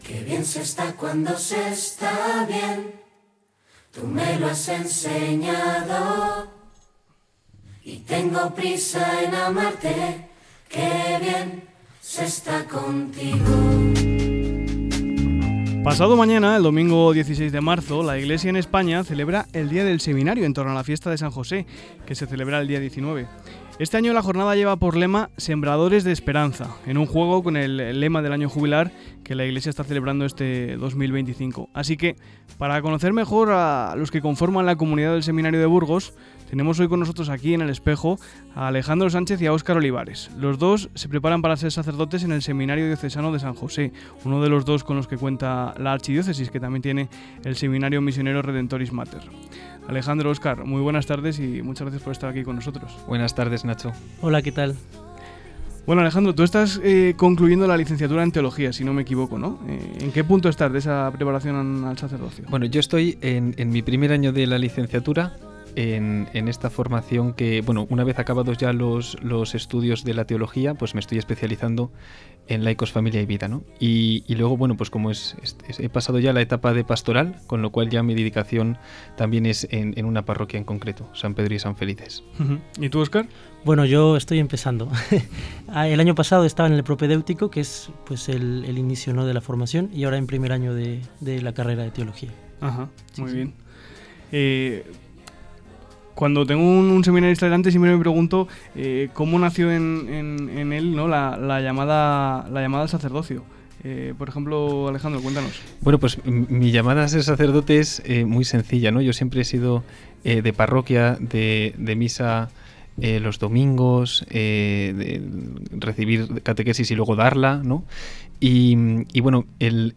Escucha aquí la entrevista completa con motivo del Día del Seminario en ‘El Espejo’ de COPE Burgos